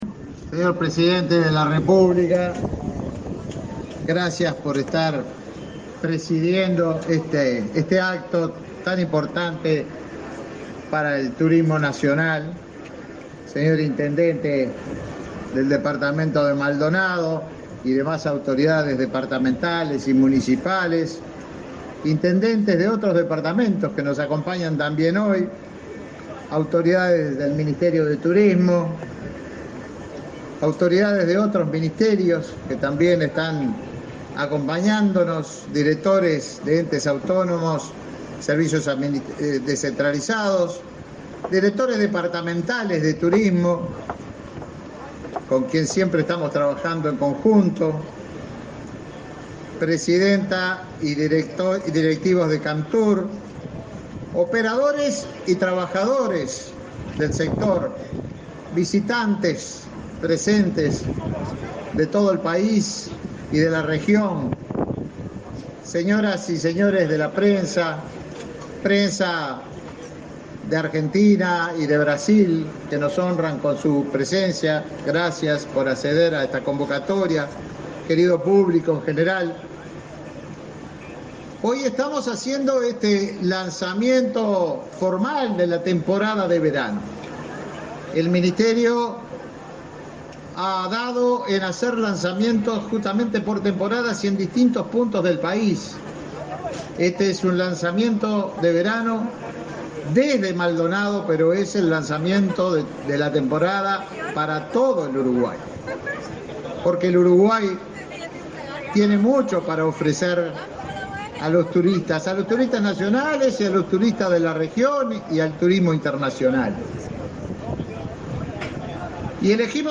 Conferencia de prensa por el lanzamiento de la temporada estival 2022-2023
Con la presencia del presidente de la República, Luis Lacalle Pou, se realizó, este 18 de noviembre, el lanzamiento de temporada estival 2022-2023.
Participaron del evento el ministro de Turismo, Tabaré Viera, y el intendente de Maldonado, Enrique Antía.